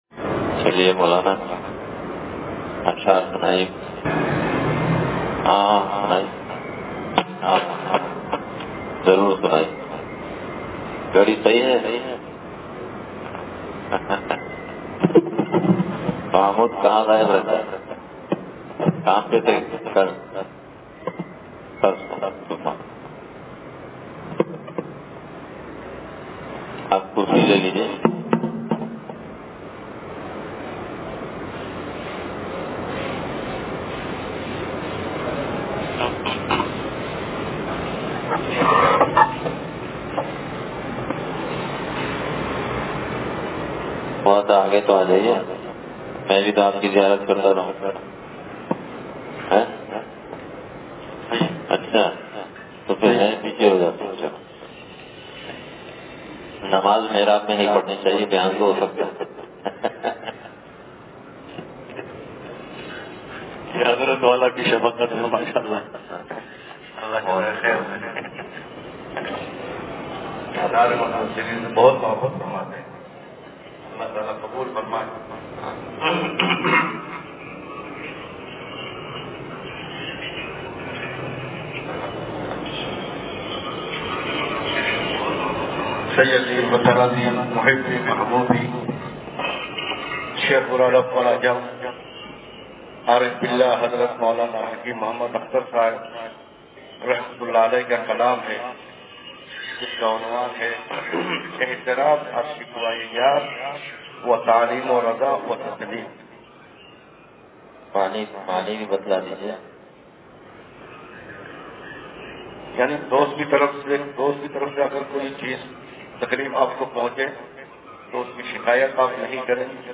بیان – انصاریہ مسجد لانڈھی